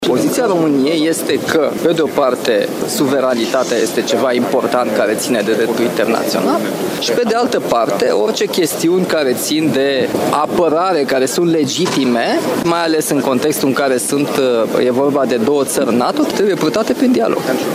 Reuniune extraordinară a Consiliului European la Bruxelles: președintele Nicușor Dan spune că Europa și România au nevoie de relația transatlantică și că este foarte bine că, în urma dialogului, tensiunile s-au diminuat.